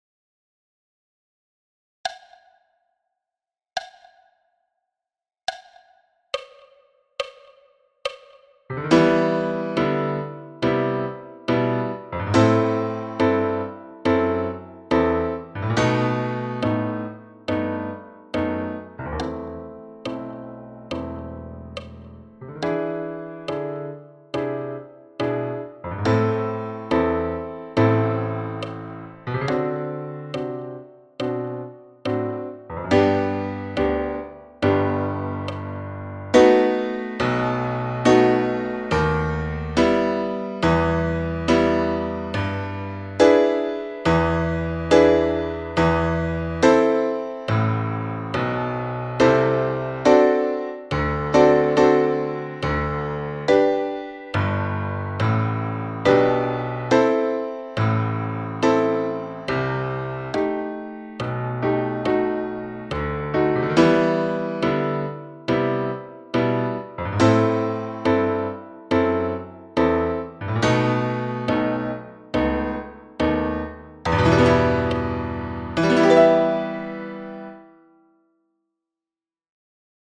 Etude n°5 – Londeix – piano solo à 70 bpm